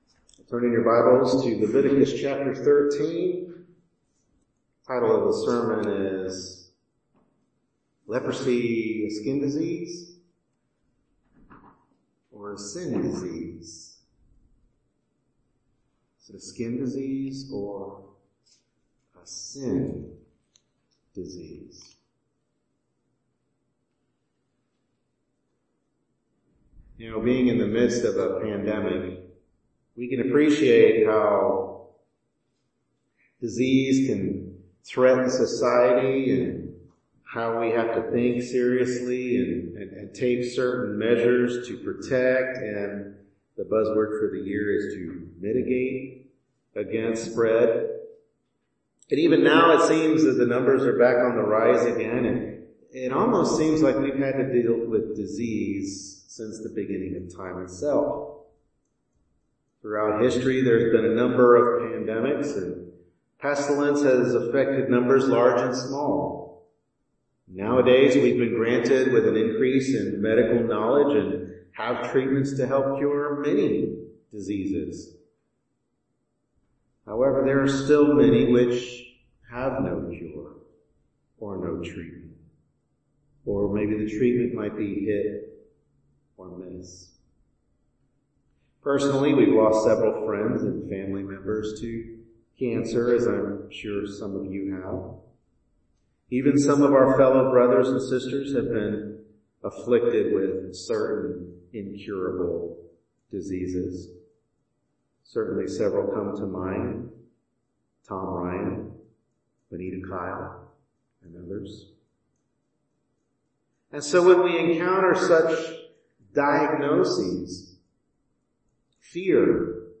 Leviticus 13 Service Type: Morning Worship Service Bible Text